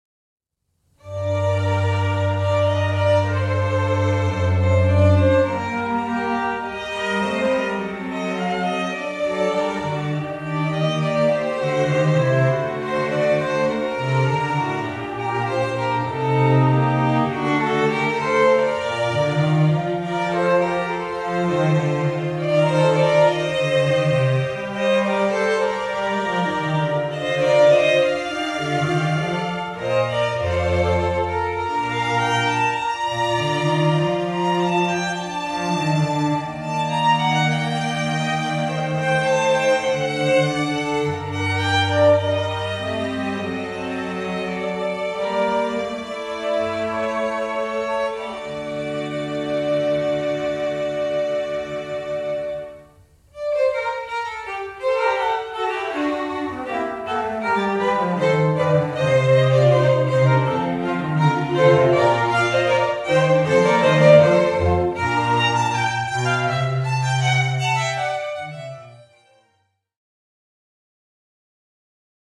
Voicing: String Quartet P